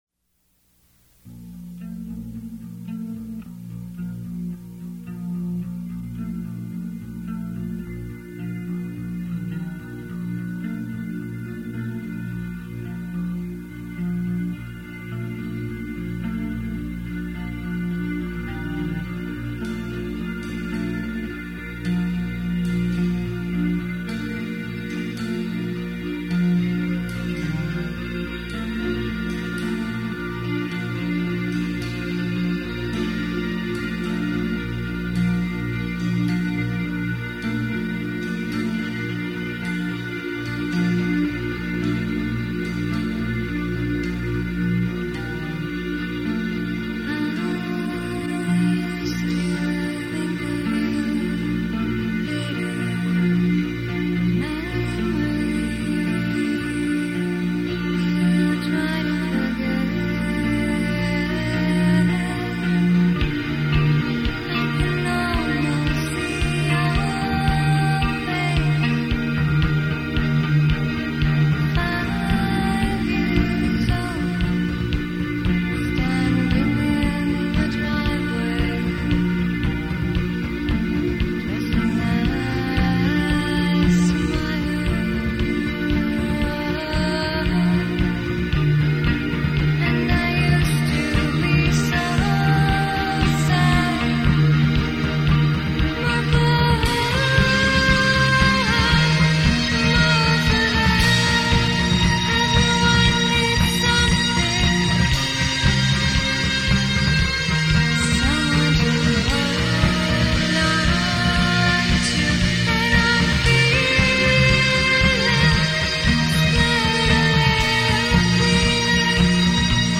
dream-pop/shoegaze